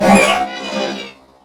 ogg / general / combat / enemy / droid / hurt1.ogg
hurt1.ogg